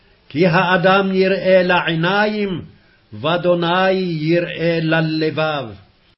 1 Sam. 16:7c Hebrew reading: